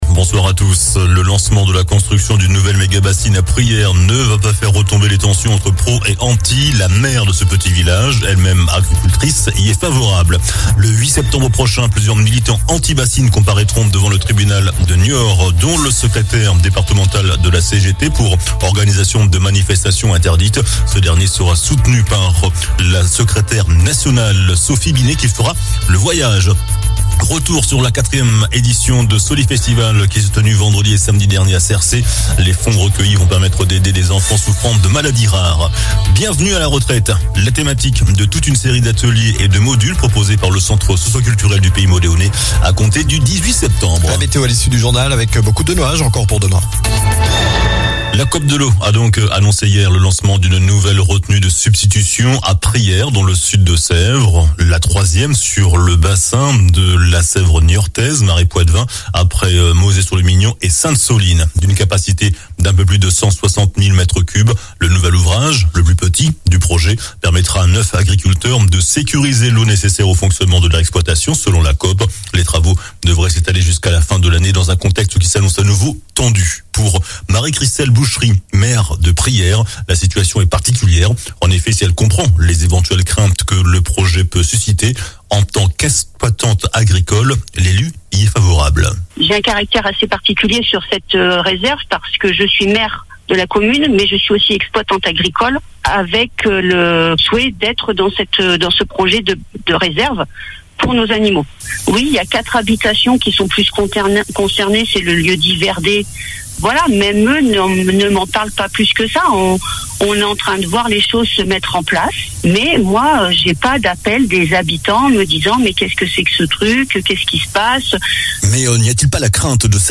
JOURNAL DU MARDI 29 AOÛT ( SOIR )